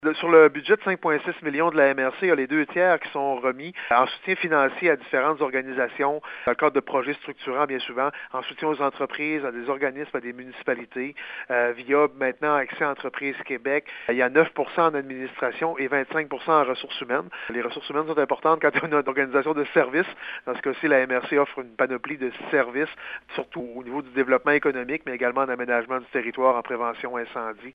Le maire de Gaspé et préfet de la MRC de La Côte-de-Gaspé, Daniel Côté, s'est adressé aux médias par téléphone et vidéoconférence ce matin.